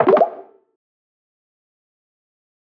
フリー効果音：ぽわぽわ
ぽわぽわ！っていう音です！泡とかが弾けたり、水の中に泡が浮かぶようなシーンにぴったり！
pock-pock.mp3